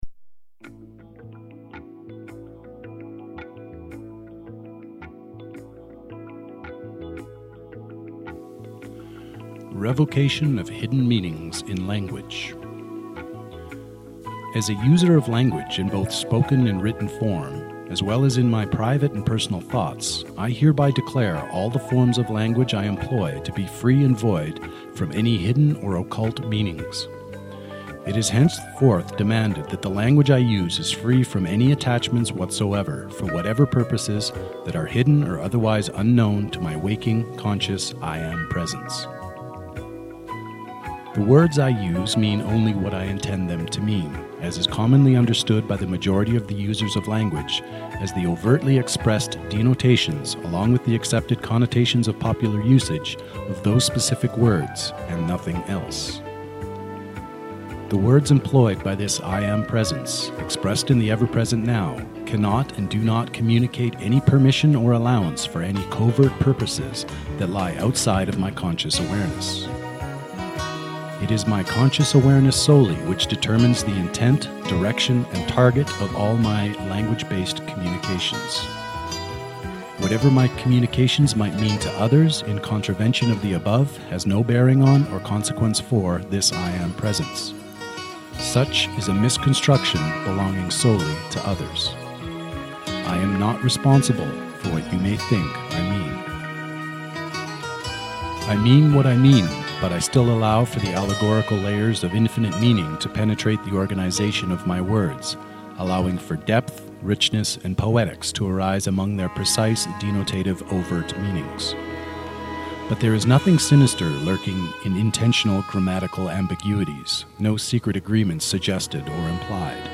(Author Narration with musical accompaniment: Hidden Moods by Happy The Man)